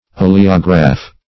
oleograph - definition of oleograph - synonyms, pronunciation, spelling from Free Dictionary
Search Result for " oleograph" : The Collaborative International Dictionary of English v.0.48: Oleograph \O`le*o*graph\, n. [L. oleum oil + -graph.] 1.